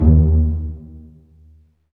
STR PIZZ.03R.wav